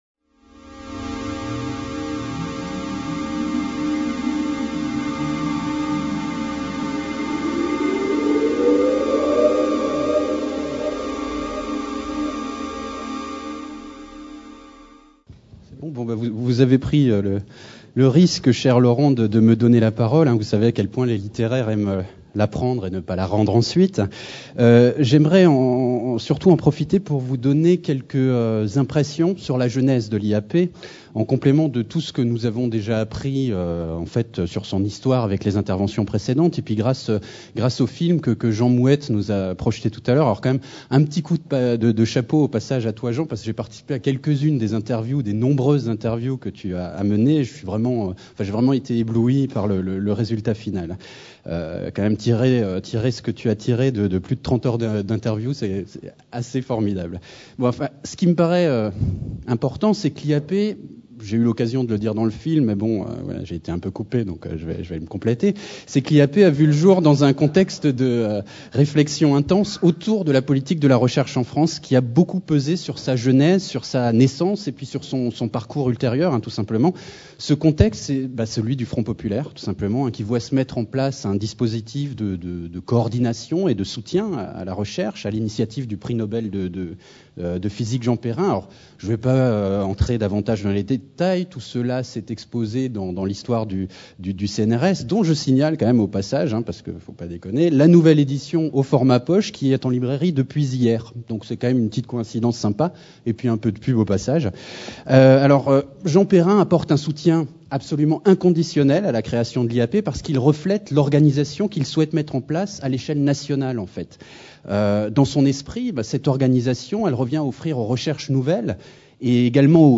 Table Ronde : bilan et perspectives de l'IAP | Canal U
Créé sous le Front populaire, rattaché au CNRS dès sa naissance en octobre 1939, l’Institut d’astrophysique de Paris fête son 75e anniversaire le 11 octobre 2013.